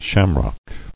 [sham·rock]